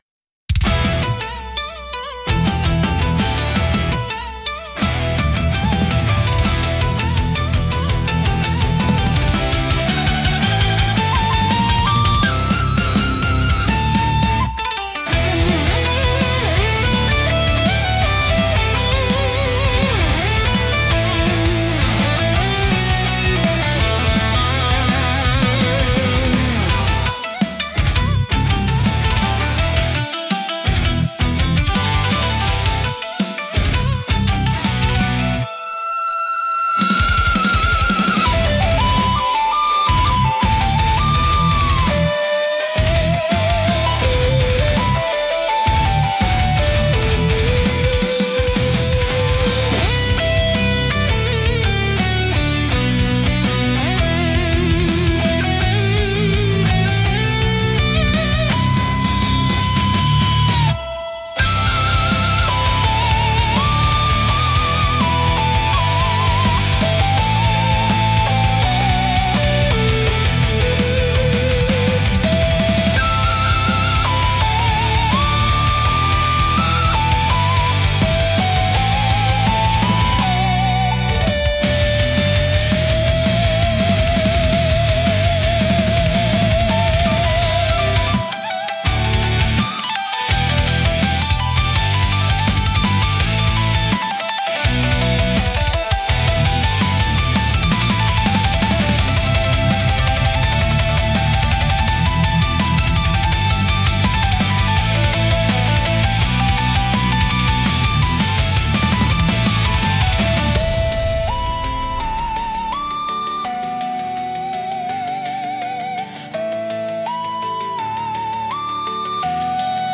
聴く人に力強さと神秘性を同時に感じさせ、日本古来の精神と現代のエネルギーを融合したインパクトを与えます。